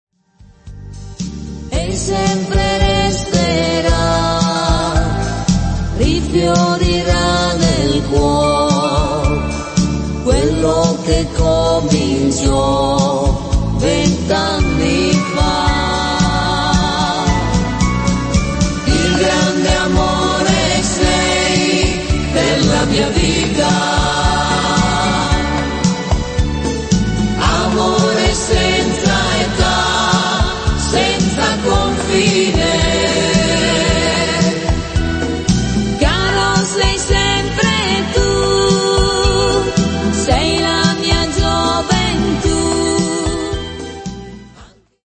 tango